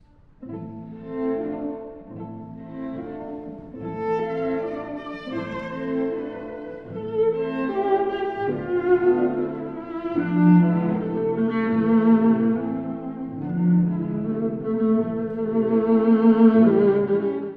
後半の3~4楽章は、ロシア民族音楽の要素が出てきます。
3楽章は、2拍子のスケルツォ風。
とても素朴で、どことなく民謡的でもあります。